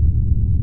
Hum.mp3